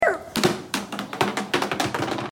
Chair Sound Effects Free Download